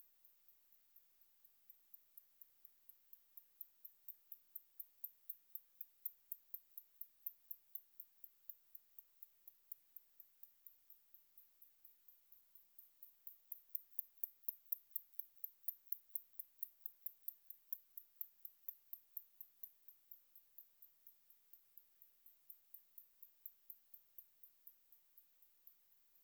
Efteråret er nemlig parringsæson for skimmelflagermusen, og i den anledning demonstrerer hannerne deres revirsang for at tiltrække hunner og for at beskytte deres territorier. Dele af revirsangen har en lav frekvens på omkring 12.000 Hertz og kan høres med det blotte øre af unge mennesker. Sangen har en tikkende lyd der kan minde om en pumpe, ventilator eller en flagstangsnor, der slår mod flagstangen.
Skimmelflagermus skriger 5 gange i sekundet, hvor sydflagermus og brunflagermus skriger henholdsvis 7 og 2 gange i sekundet.
Hvert skrig starter med en høj frekvens på knap 40.000 Hertz og slutter omkring de 12.000 Hertz.
skimmelflagermus-revirsang.wav